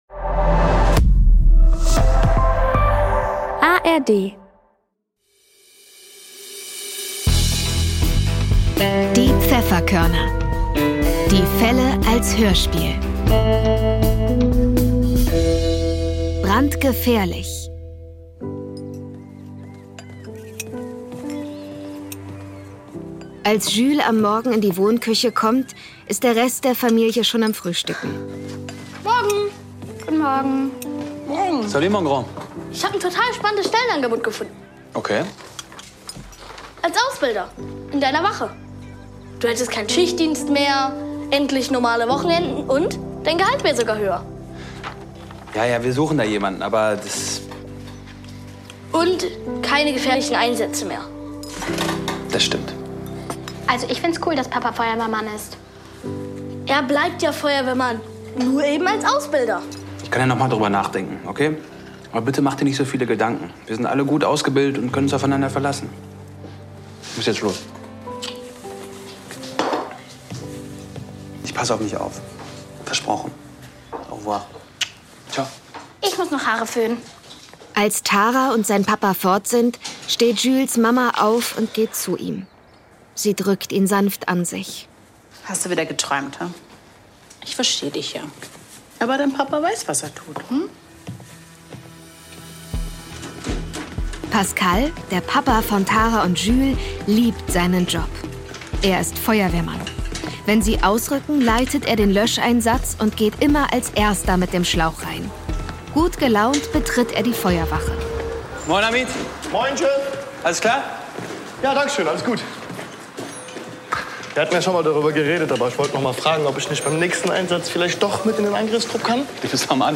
Brandgefährlich (4/21) ~ Die Pfefferkörner - Die Fälle als Hörspiel Podcast